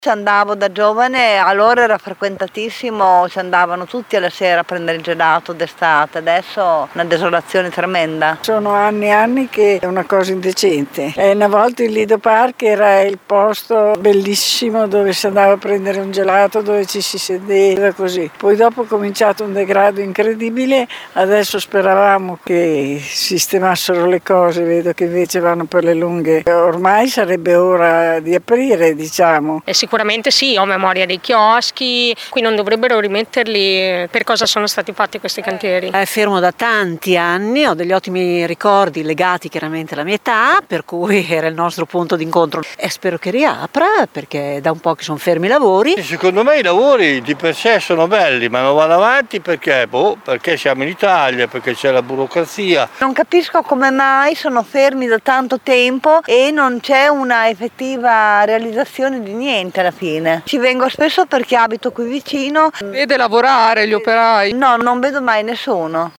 Sentiamo alcuni cittadini:
VOX-LIDO-PARK.mp3